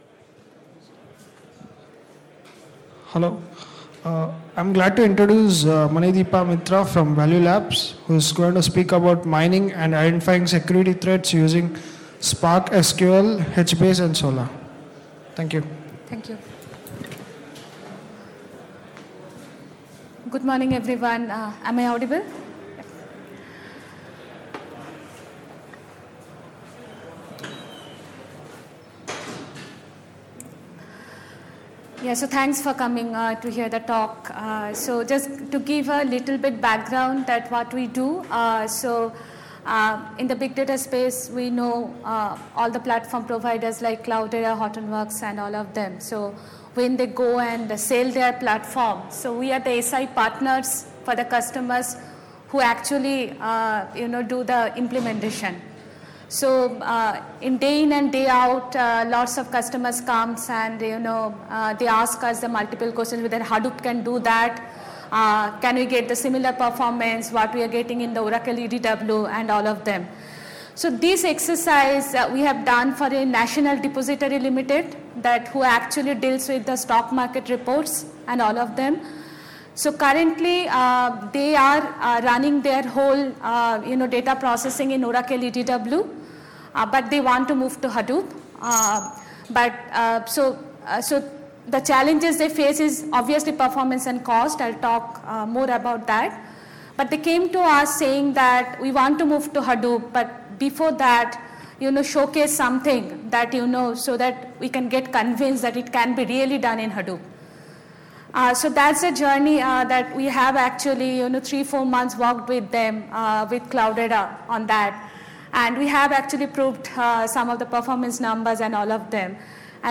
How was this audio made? Apache Big Data Seville 2016